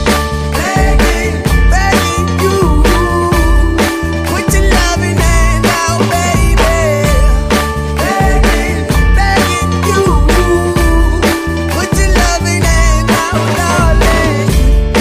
мужской вокал
Хип-хоп
веселые
RnB